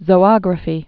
(zō-ŏgrə-fē)